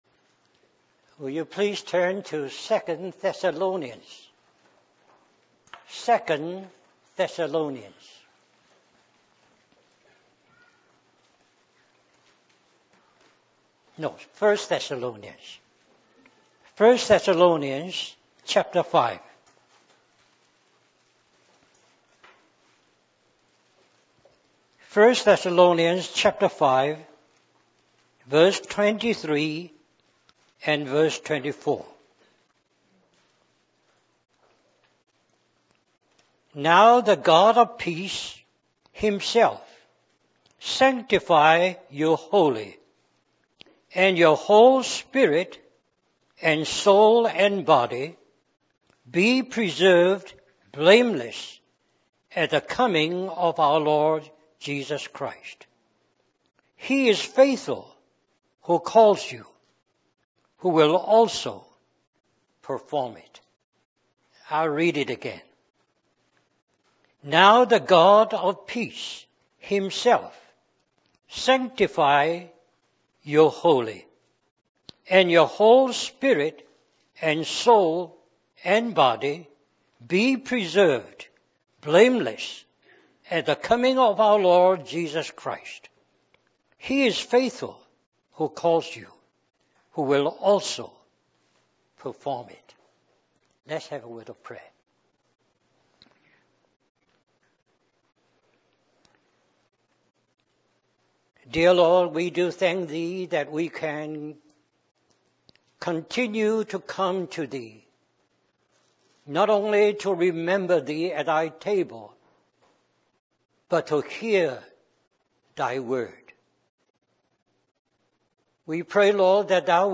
In this sermon, the speaker emphasizes the importance of sanctification in every aspect of our being - spirit, soul, and body.